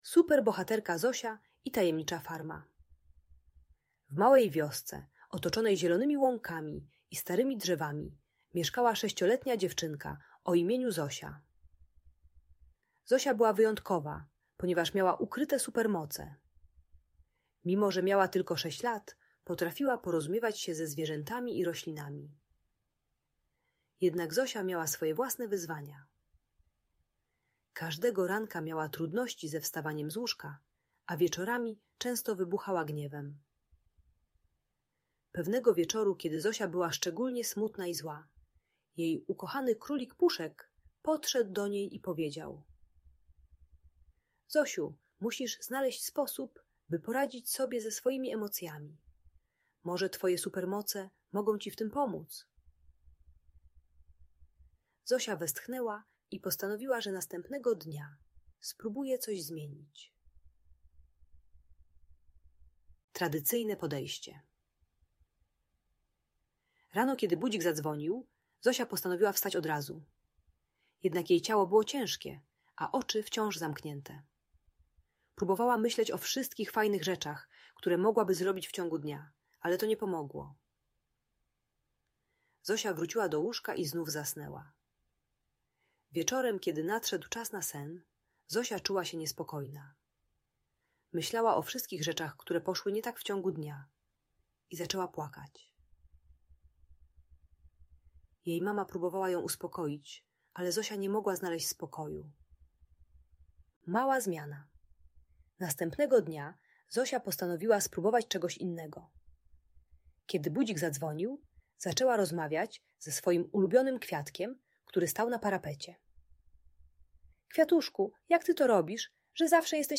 Superbohaterka Zosia i Tajemnicza Farma - Audiobajka